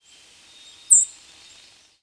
White-throated Sparrow diurnal flight calls
Fig.6. Minnesota August 30, 1987 (WRE).
Perched bird.